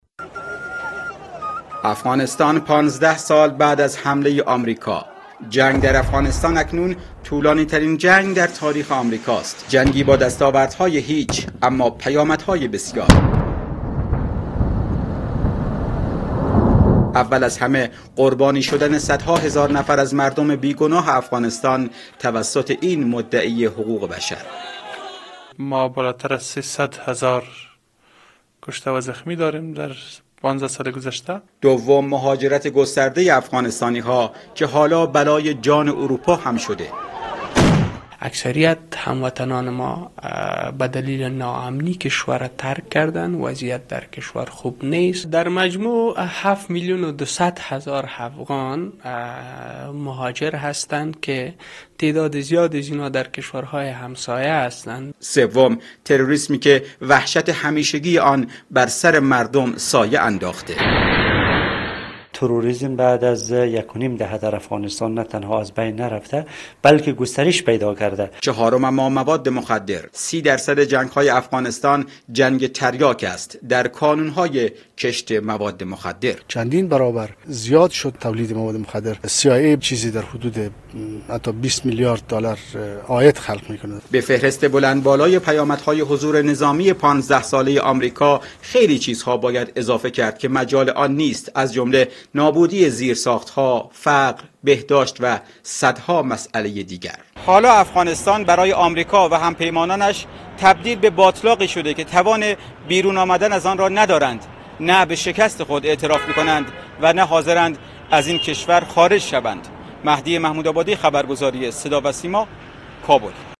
дар Кобул аз осори мухарриби ин ҳамла гузориш медиҳад: